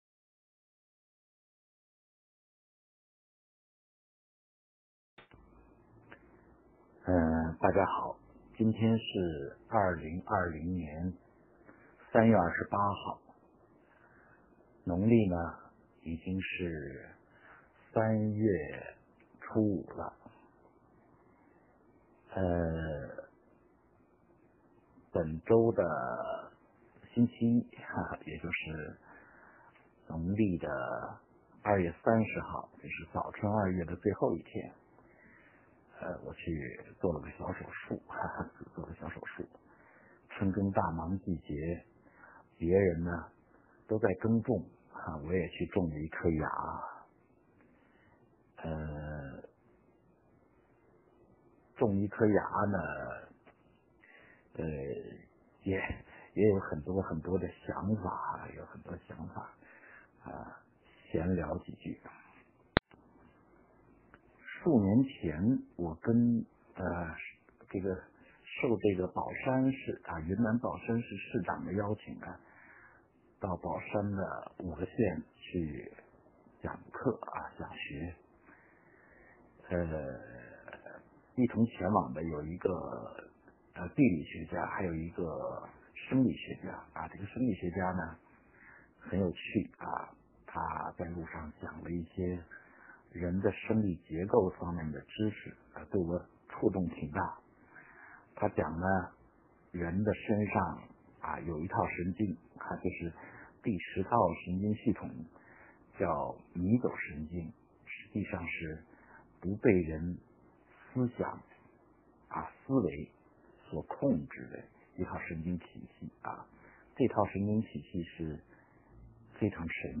7-卢麒元 《阳明心学》讲座 第08讲：迷走神经